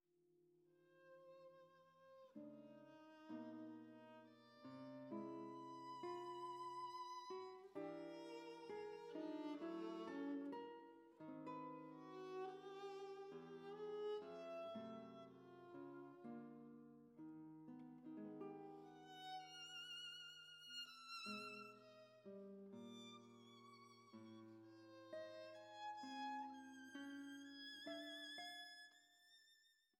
serieller Musik